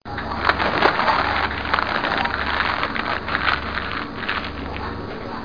PAPER.mp3